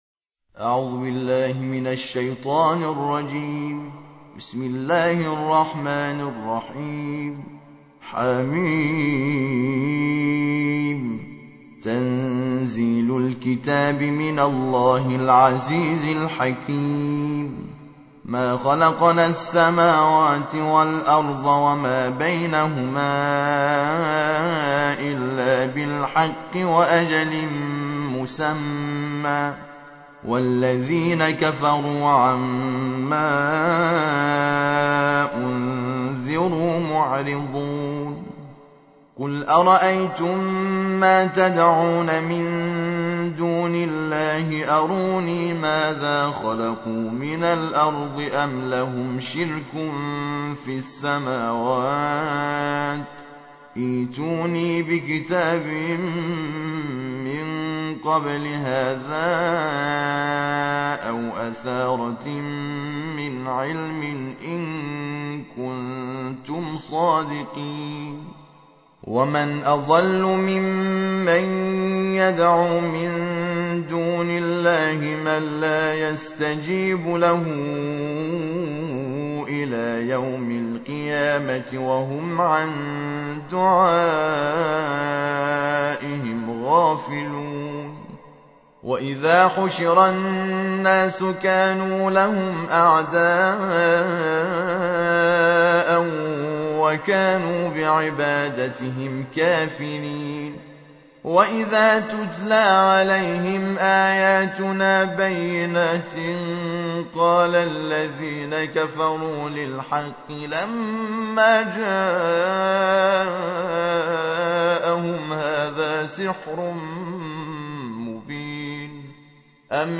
تکیه | ترتیل جزء بیست و ششم قرآن کریم
ترتیل جزء بیست و ششم قرآن کریم - حامد شاکر نژاد با ترافیک رایگان